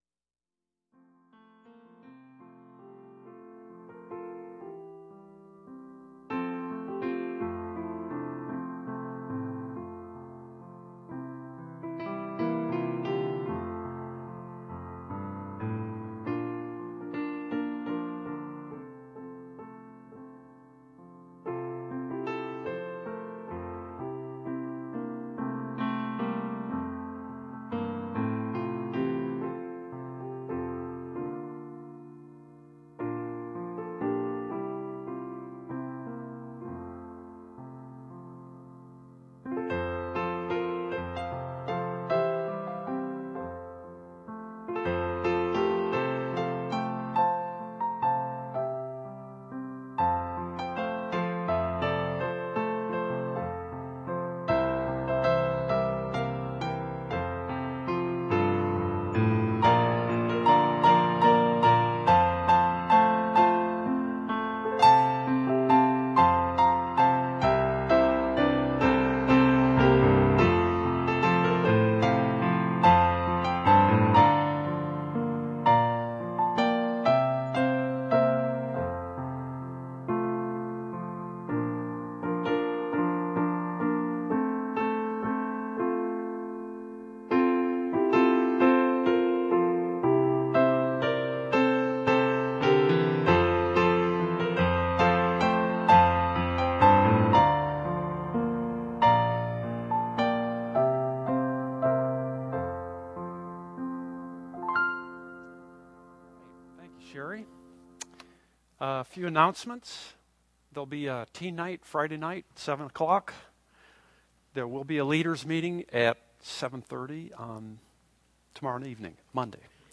In this teaching the first three verses of Hebrews chapter one are explained and we see that Jesus Christ carried, took care of our sins by means of the powerful Word of God. By believing on the accomplished work of Jesus Christ in a moment of time we pass from death to eternal life.